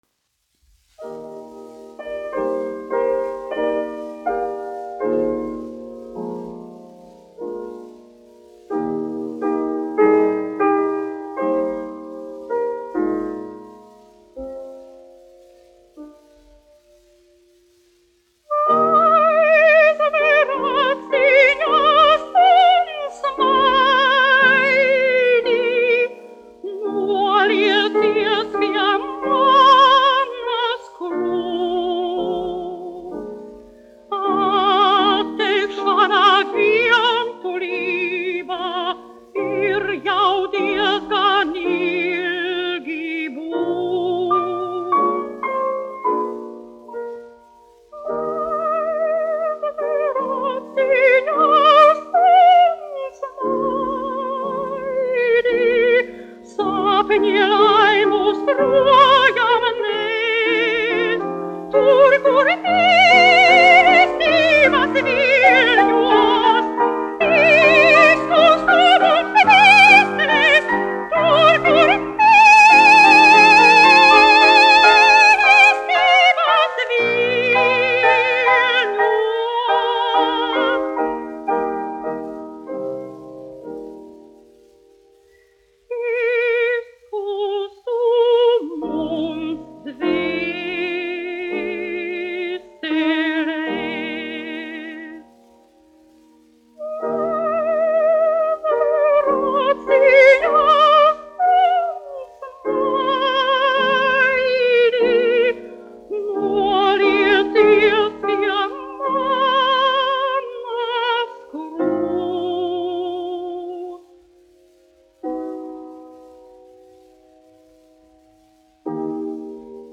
1 skpl. : analogs, 78 apgr/min, mono ; 25 cm
Dziesmas (augsta balss) ar klavierēm
Skaņuplate